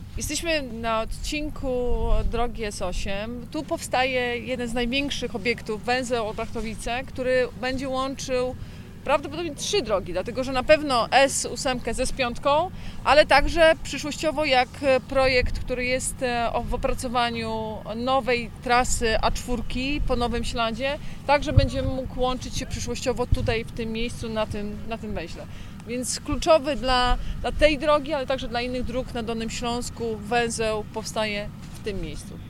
Intensywne prace trwają też na węźle Olbrachtowice, który będzie jednym z największych obiektów na trasie S8. O tym, co to oznacza mówi Anna Żabska, wojewoda dolnośląska.